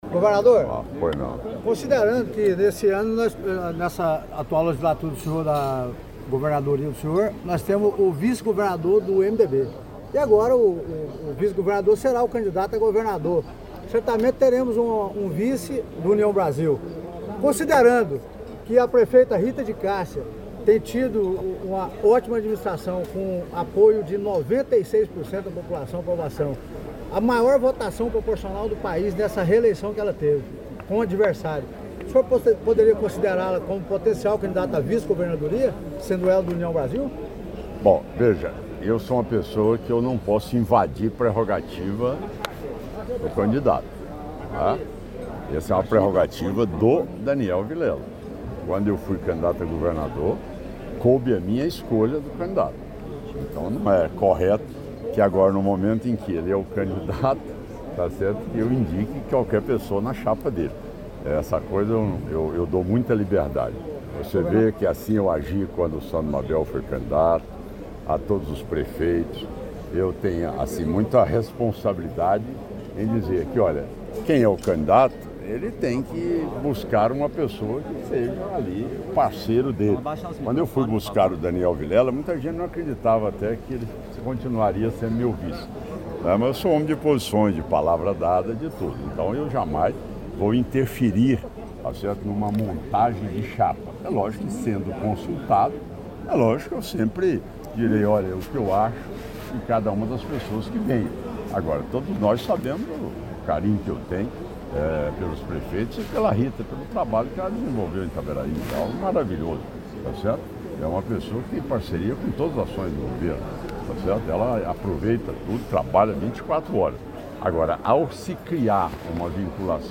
Em uma entrevista coletiva durante um café da manhã com a imprensa do interior, realizado no Palácio das Esmeraldas, em Goiânia, o governador de Goiás, Ronaldo Caiado, destacou os avanços no projeto de duplicação da rodovia GO-330, que conecta Catalão a Goiânia, capital de Goiás.